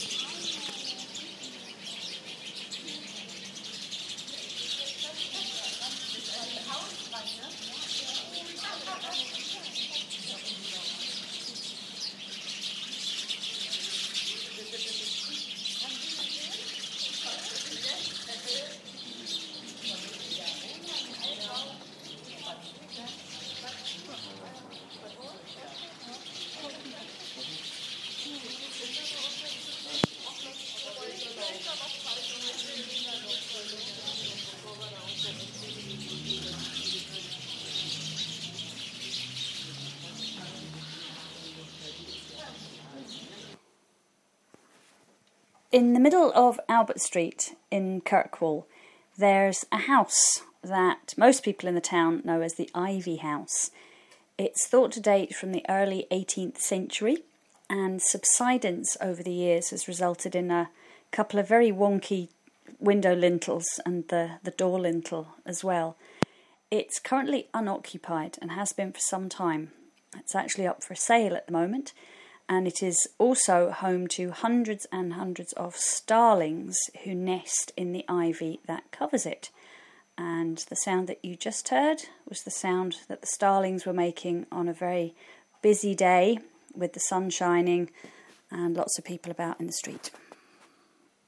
Starlings in the Ivy House, Kirkwall
After the sound of the birds, I give a short explanation :)